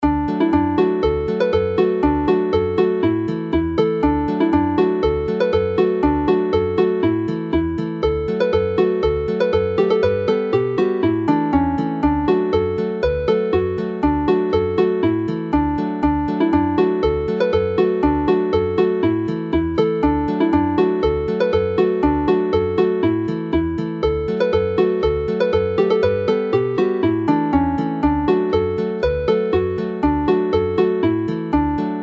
Hela'r Geinach (Hunting the Hare) is clearly another pipe tune which is more lively whilst Aden y Frân Ddu (the Black Crow's Wing) is livelier still.